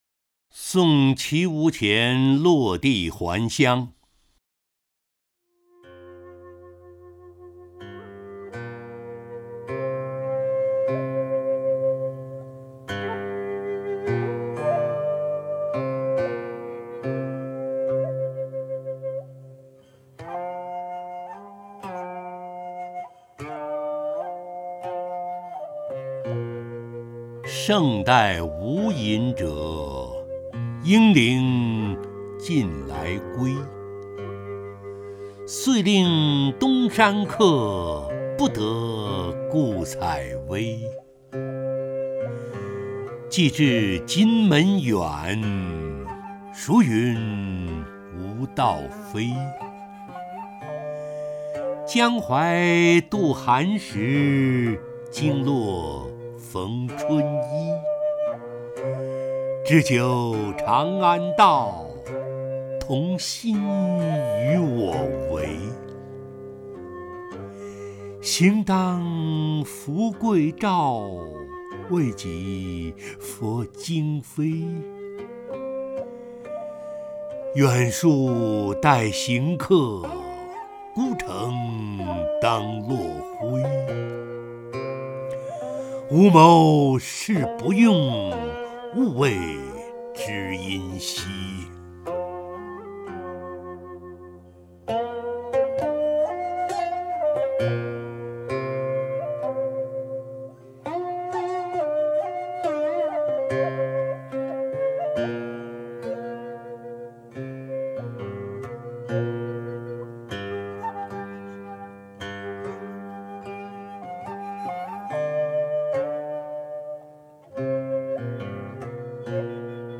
陈醇朗诵：《送綦毋潜落第还乡》(（唐）王维)